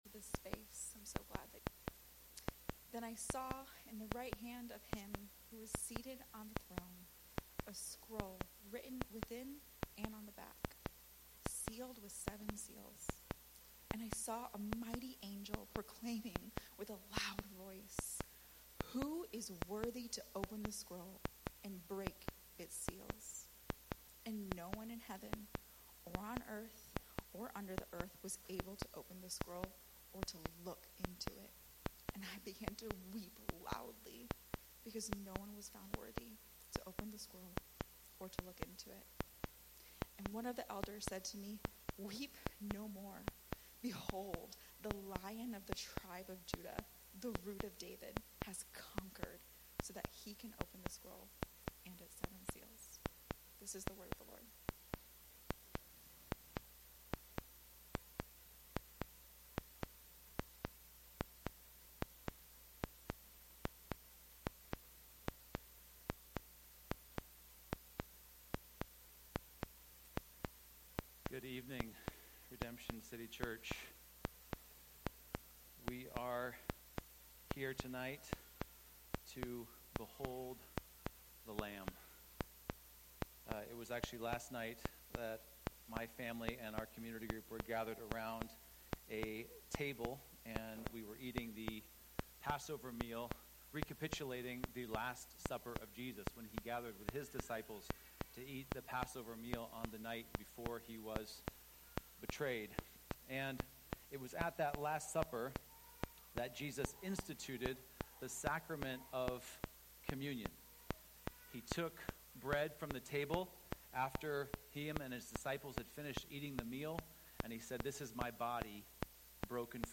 Sermons by Redemption City Church